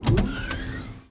openDoor.wav